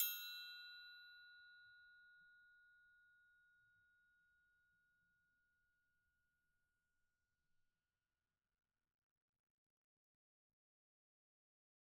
Triangle3-Hit_v2_rr1_Sum.wav